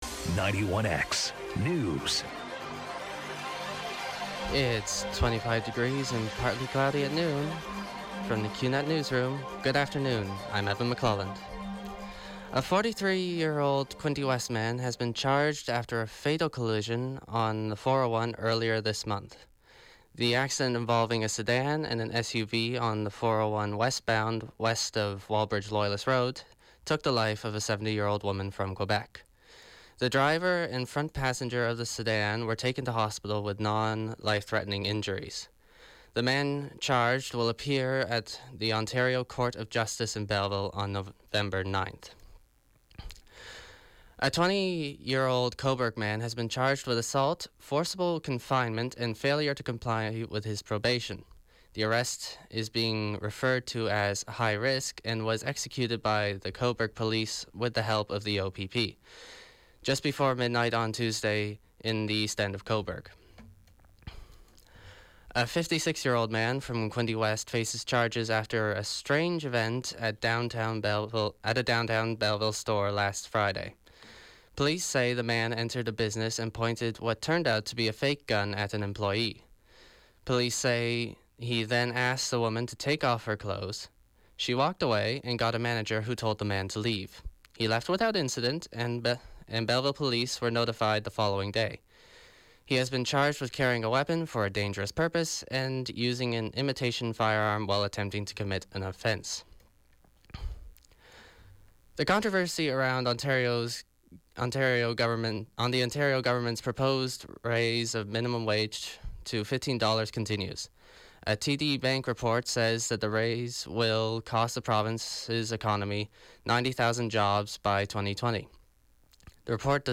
91X FM Newscast: Wednesday, September 27, 2017, 12 p.m.